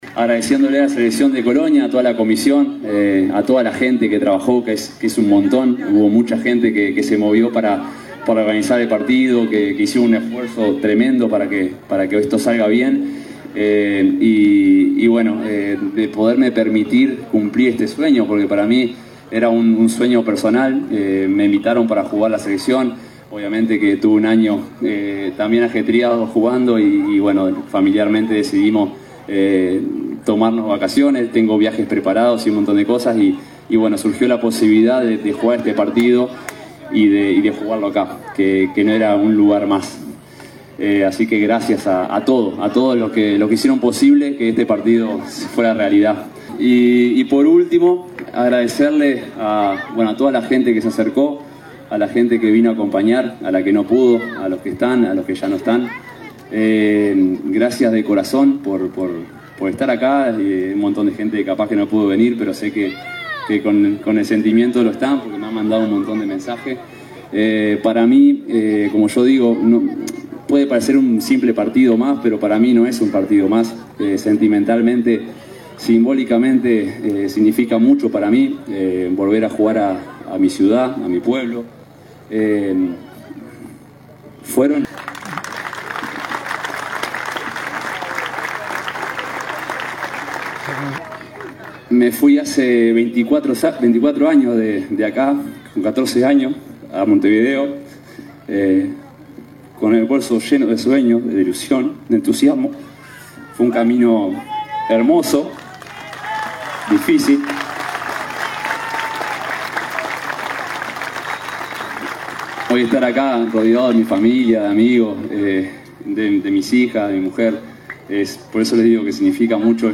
Escuchamos parte de lo que expresó Diego Godín en momentos de ser reconocido.